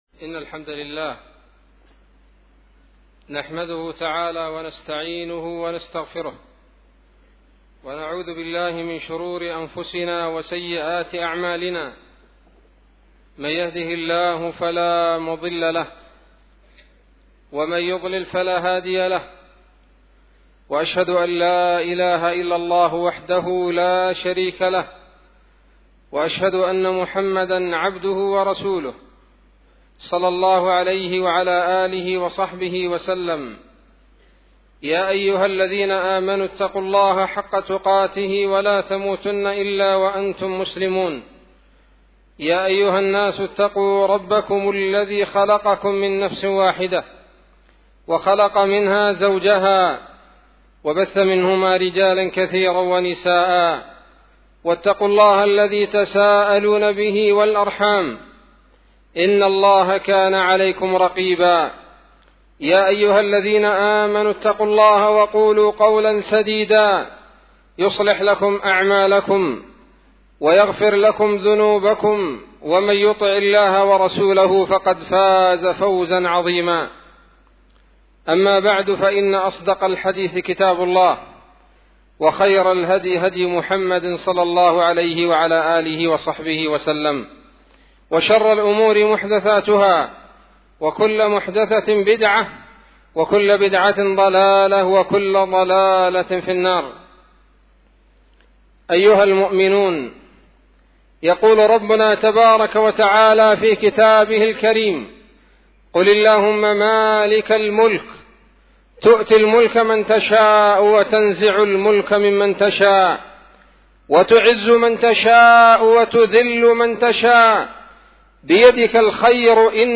خطبة بعنوان : ((أسباب الرفعة))